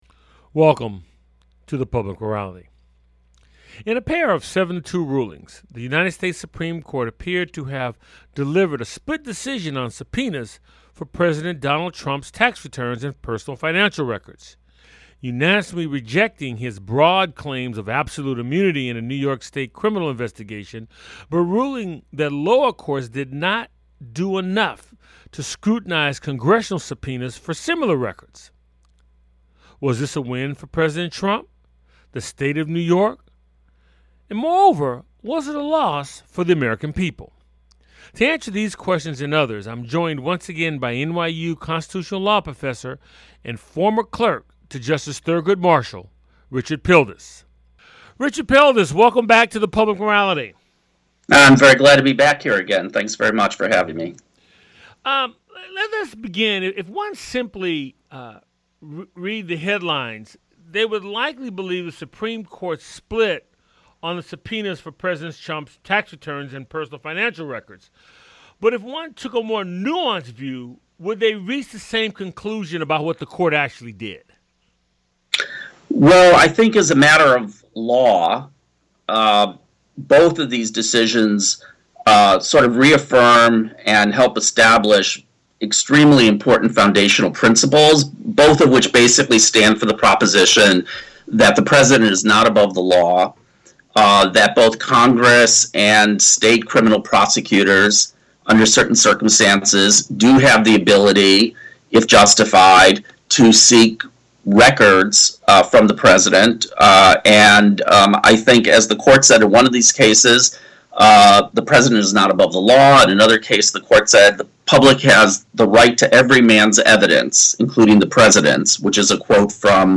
The show airs on 90.5FM WSNC and through our Website streaming Tuesdays at 7:00p.